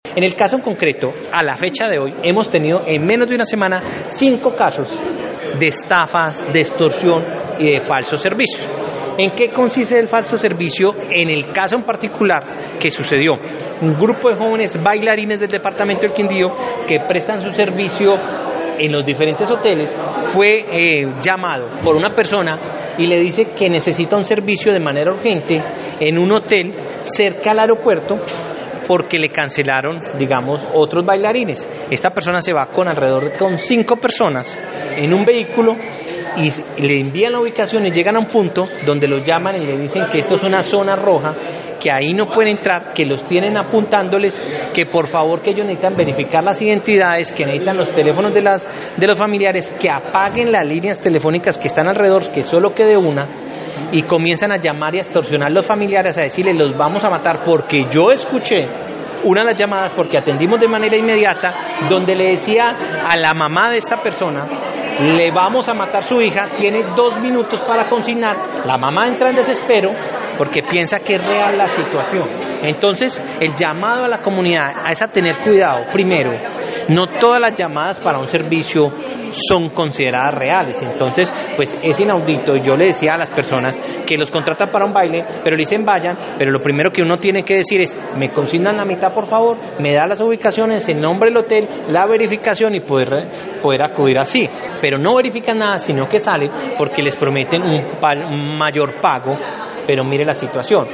Jaime Andrés Pérez, secretario del Interior del Quindío